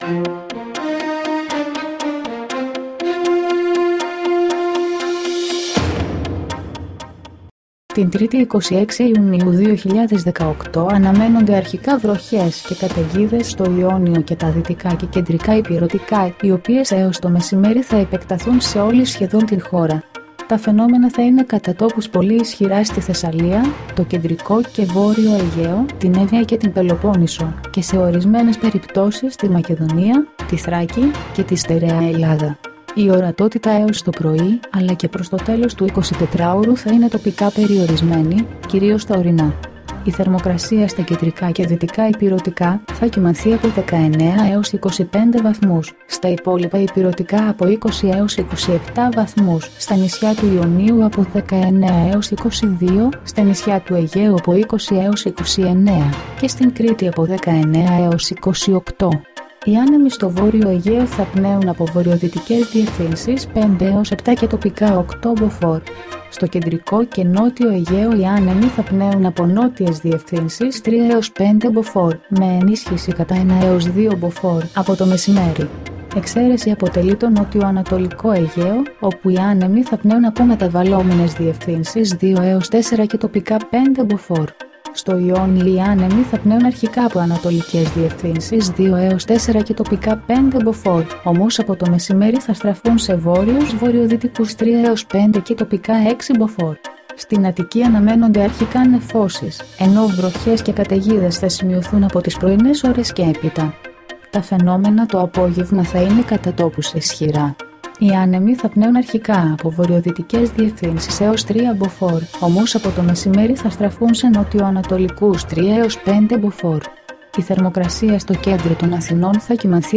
dailyforecast004-1.mp3